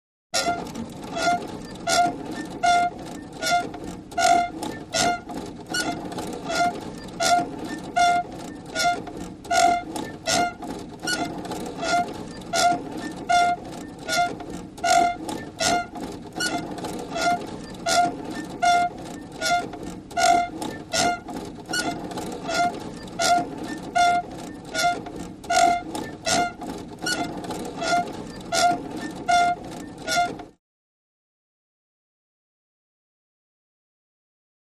Metal Squeak | Sneak On The Lot